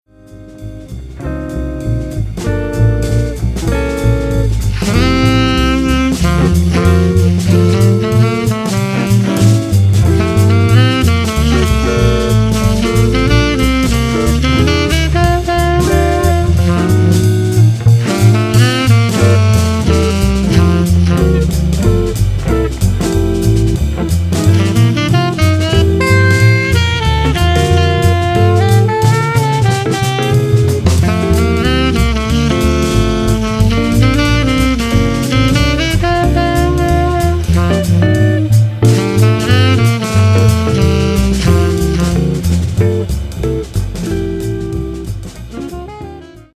smooth background music and grooving modern Jazz sounds
Saxophone, Double & Electric bass, Guitar and Drums